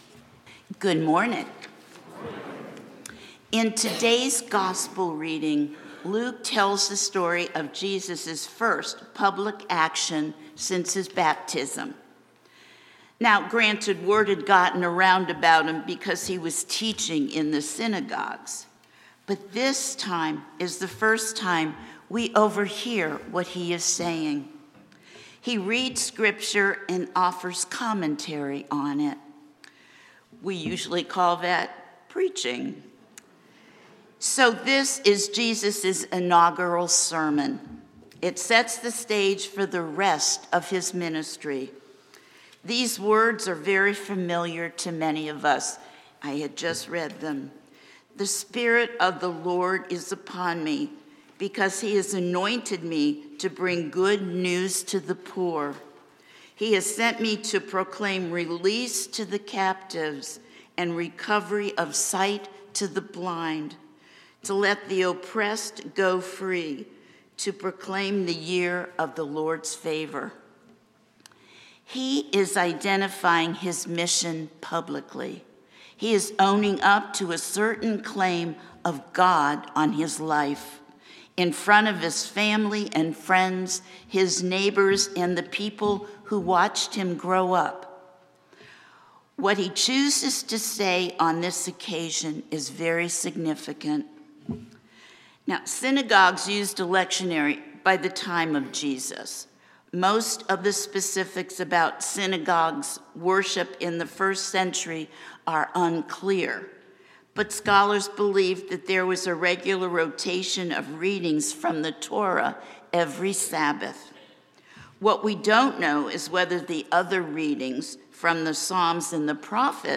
St-Pauls-HEII-9a-Homily-26JAN25.mp3